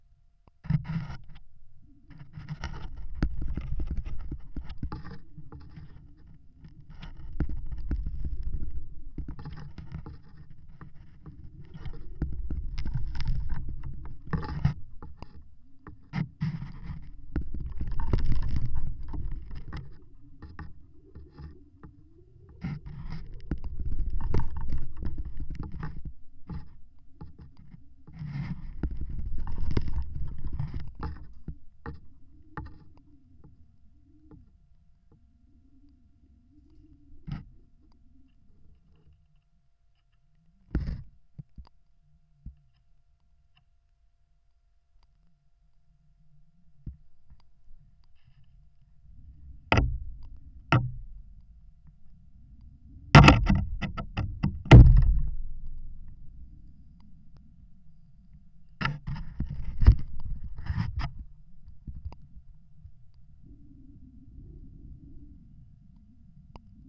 Tree Listening
I also tried adhering the microphones to two locations on one low-hanging branch. The left channel (ear) microphone was placed closest to the trunk and the right channel (ear) microphone was placed further out along the branch. I then began repeatedly bending the branch.
Rather than generating a sound with an external device and recording that sound as it travels through the tree, the recording of the branch bending is the sound of the tree itself as it responds to an external stress. The result is much richer and the difference in location of the microphones is audibly detected.